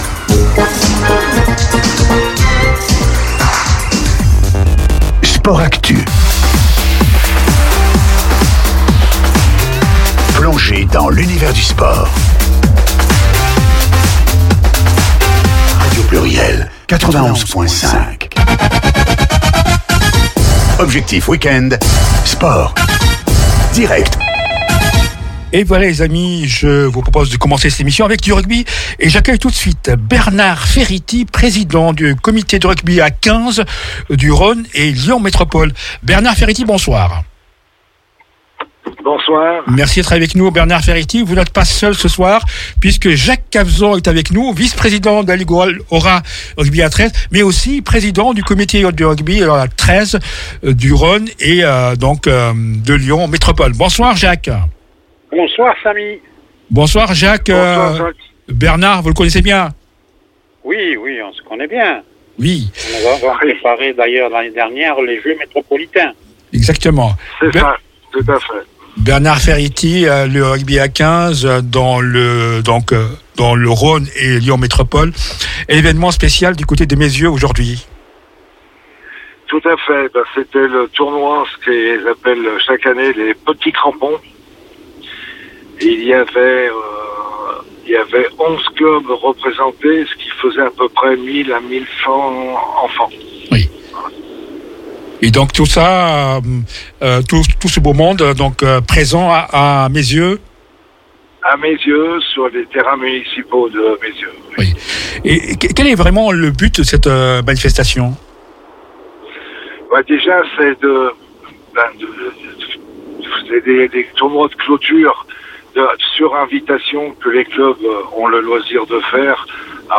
L’interview du président mois de JUILLET 2025